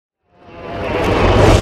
Minecraft Version Minecraft Version snapshot Latest Release | Latest Snapshot snapshot / assets / minecraft / sounds / mob / warden / sonic_charge1.ogg Compare With Compare With Latest Release | Latest Snapshot
sonic_charge1.ogg